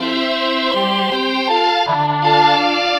Track 16 - Synth Strings 01.wav